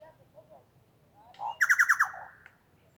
Chucao (Scelorchilus rubecula)
Nombre en inglés: Chucao Tapaculo
Localización detallada: Parque Municipal Llao-llao
Condición: Silvestre
Certeza: Fotografiada, Vocalización Grabada